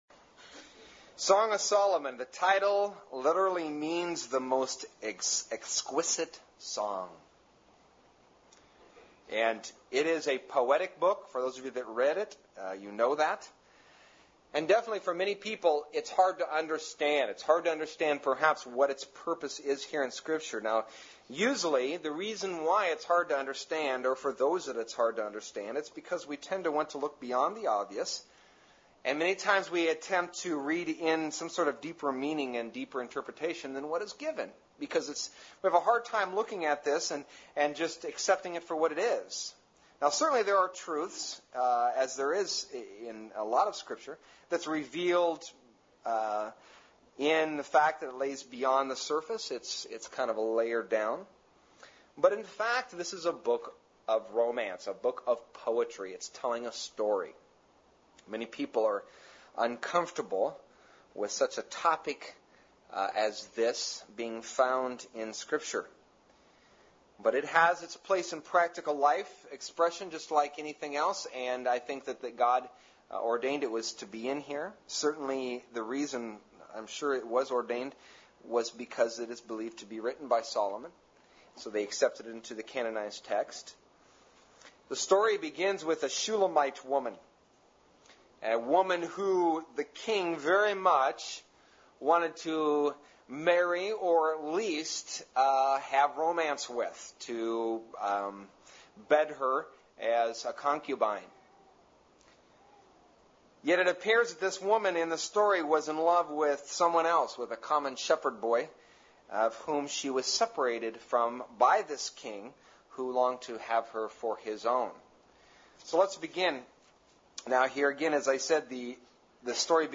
1 Sermons